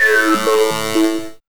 5204R SYNSWP.wav